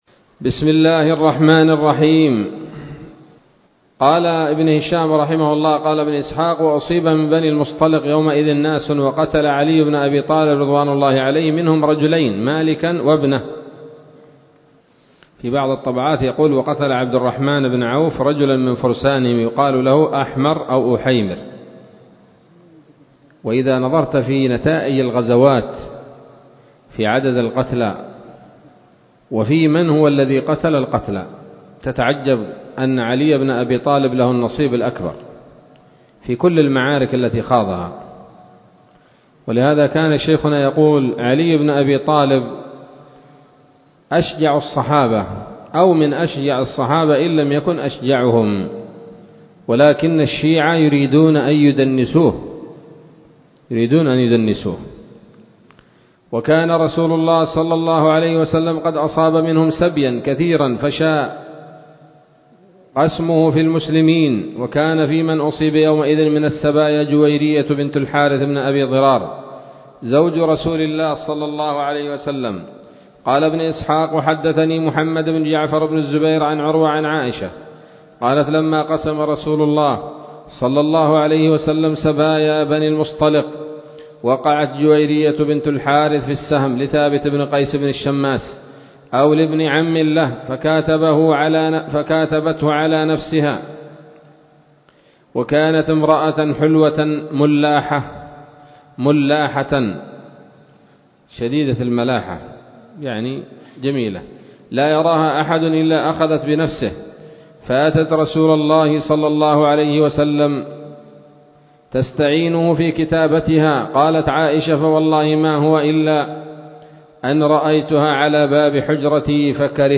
الدرس الخامس والعشرون بعد المائتين من التعليق على كتاب السيرة النبوية لابن هشام